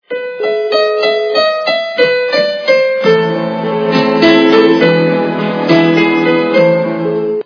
» Звуки » звуки для СМС » ставим на входящие SMS